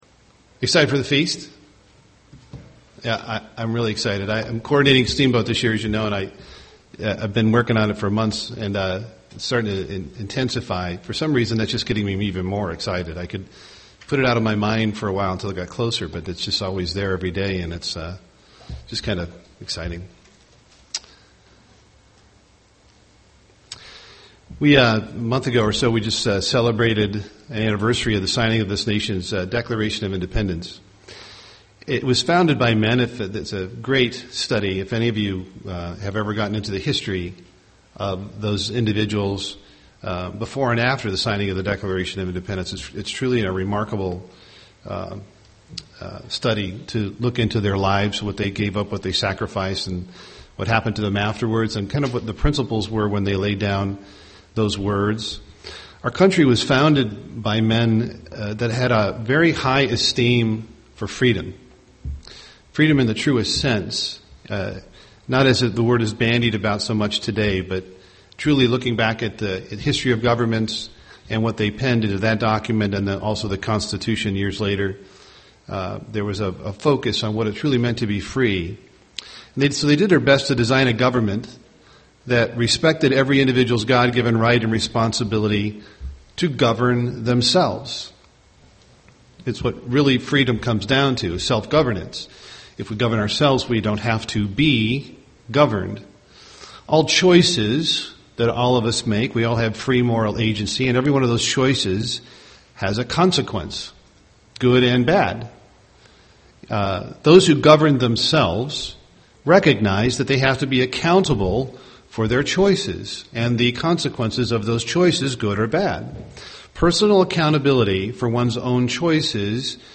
UCG Sermon accountability self government freedom Studying the bible?